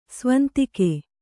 ♪ svantike